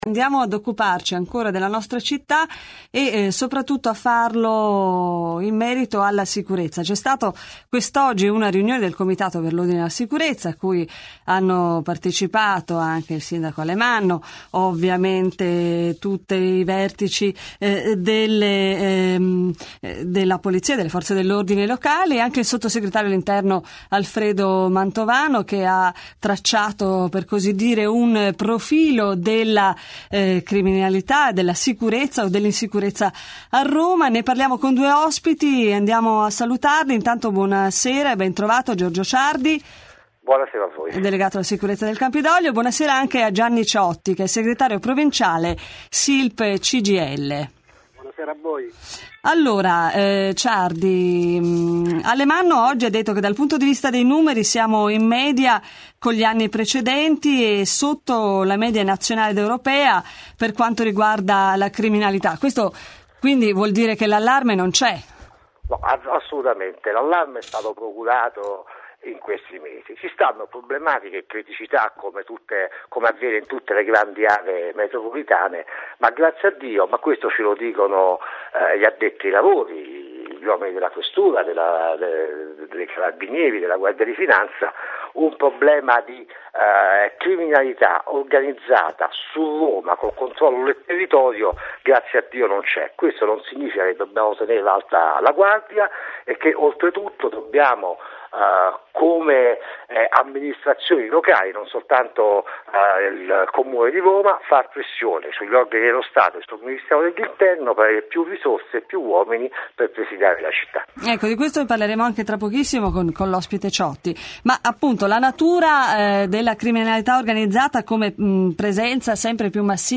La 'Sicurezza' come diritto di libert�. Intervista